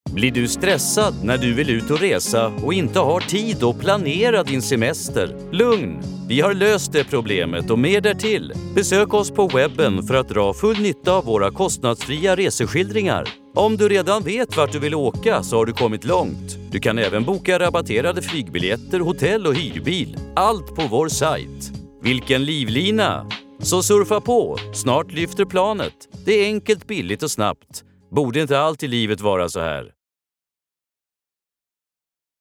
Soft